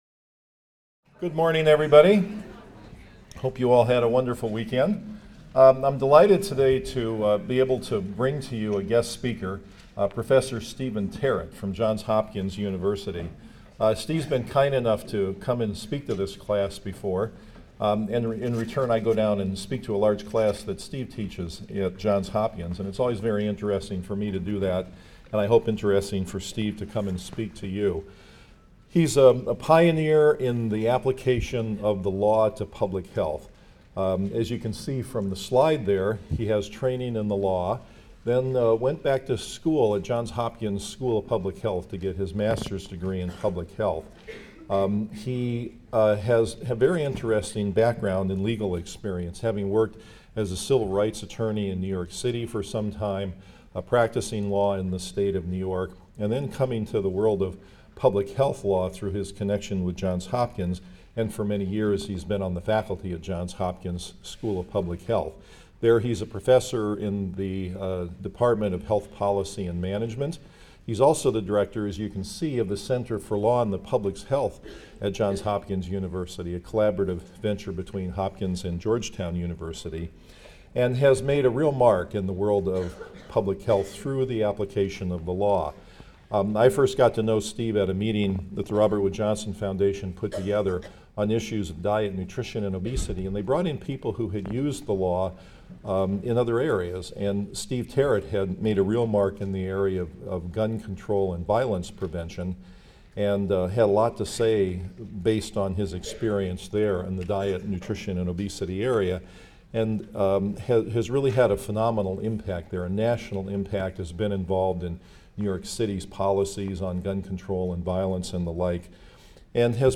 PSYC 123 - Lecture 19 - The Law and Opportunities to Improve Nutrition and Health